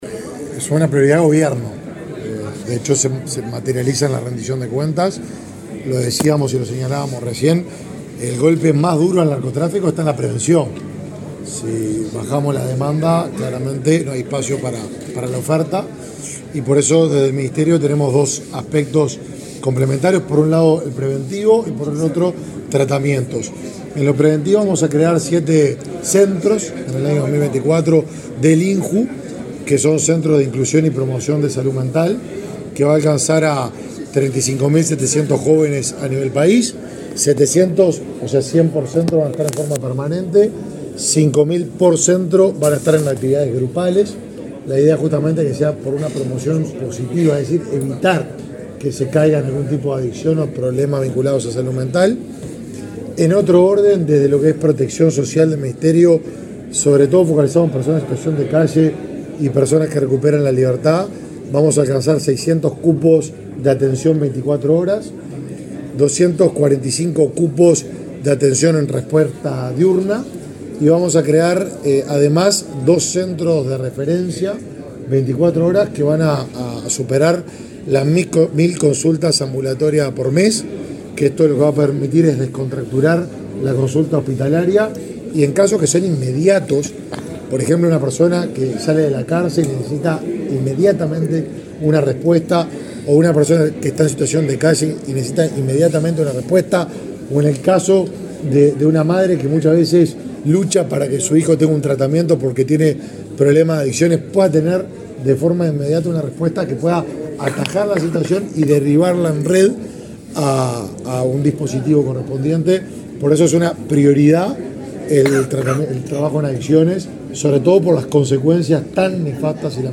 Declaraciones del ministro de Desarrollo Social, Martín Lema
El ministro de Desarrollo Social, Martín Lema, dialogó con la prensa luego de participar, en Montevideo, en el Encuentro Nacional de Juntas